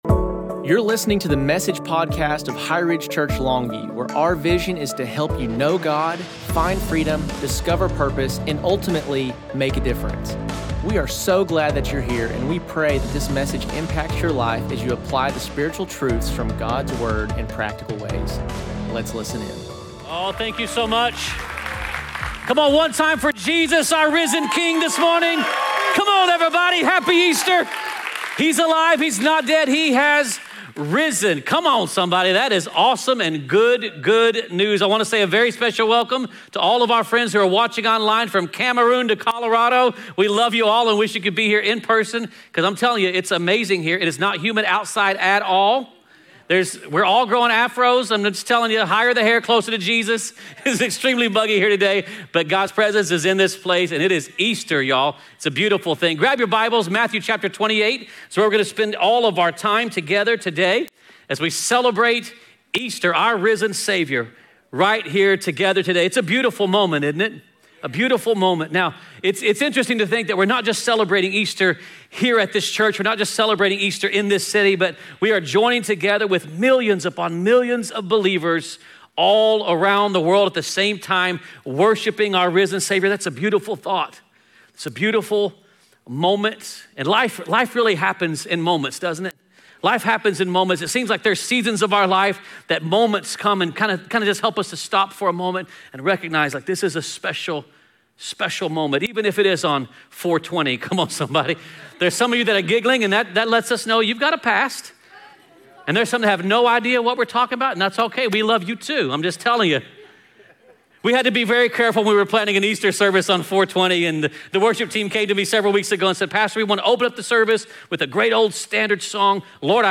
Message: Easter 2025